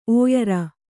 ♪ ōyara